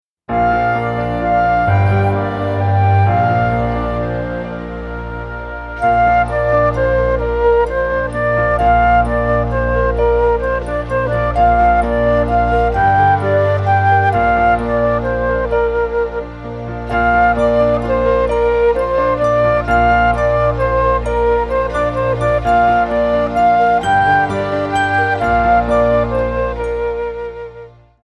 Recueil pour Flûte traversière